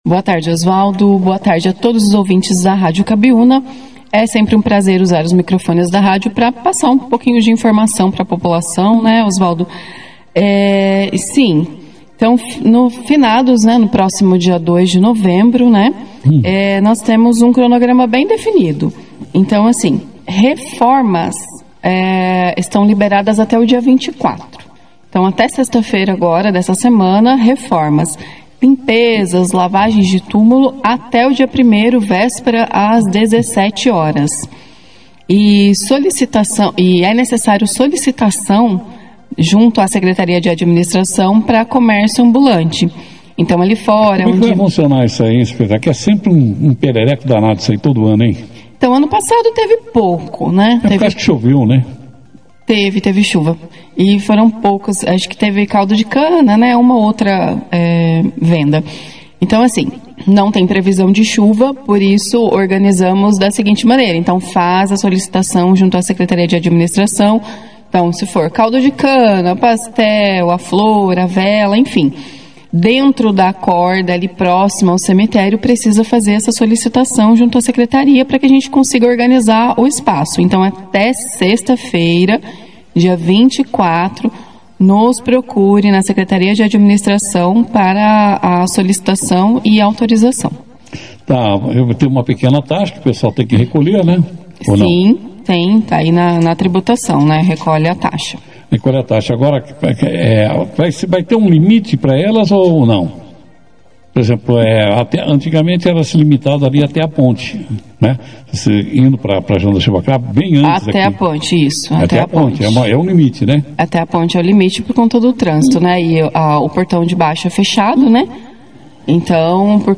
A secretária de Administração de Bandeirantes, Claudia Jans, (foto), participou nesta terça-feira, 21 de outubro, da 2ª edição do Jornal Operação Cidade, para falar sobre o cronograma e as orientações referentes ao Dia de Finados, que será celebrado em 2 de novembro.
Durante a entrevista, Claudia falou dos prazos para reformas e reparos nos túmulos, além das regras para limpeza e lavagem dentro do cemitério municipal. A secretária também reforçou que os comerciantes ambulantes que desejarem trabalhar nas proximidades do cemitério devem estar devidamente regularizados junto à Secretaria de Administração.